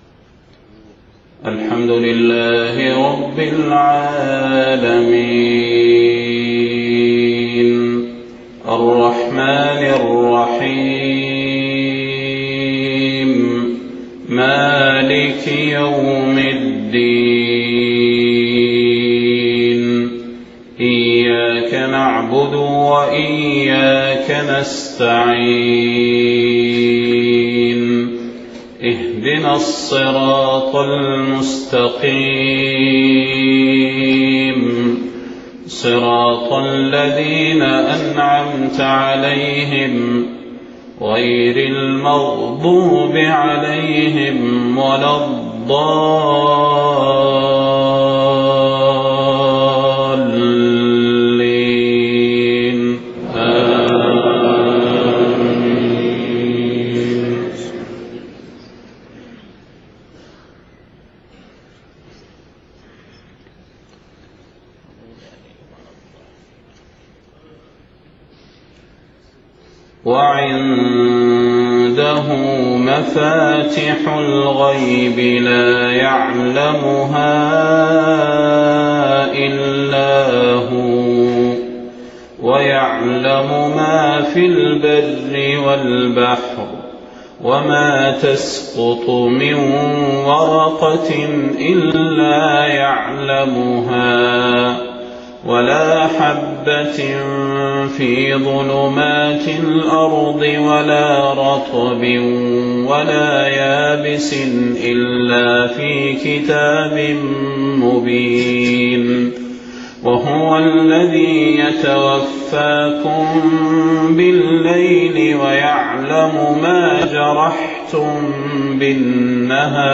صلاة الفجر 3 صفر 1430هـ من سورة الأنعام 59-73 > 1430 🕌 > الفروض - تلاوات الحرمين